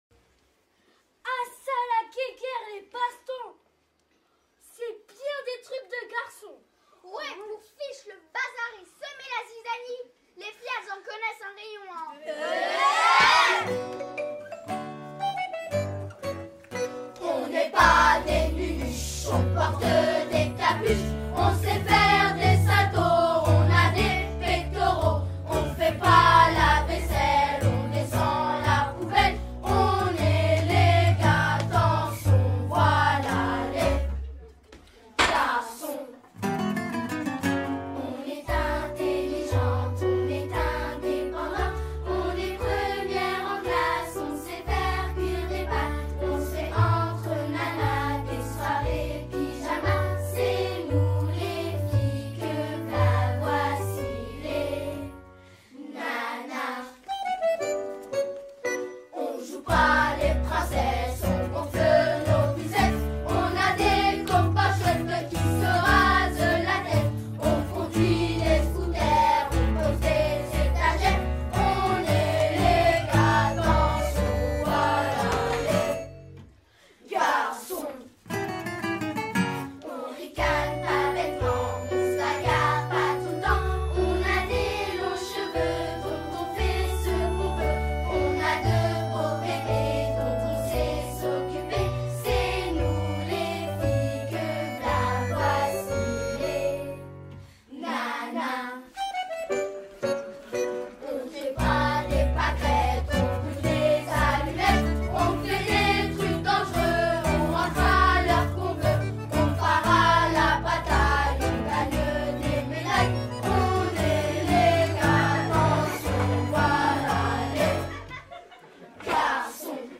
Après la comédie musicale T'es qui dis, t'es d'où ?, les petits Serruriers Magiques font entendre leurs voix pour promouvoir les Droits de l'enfant !
vingt cinq enfants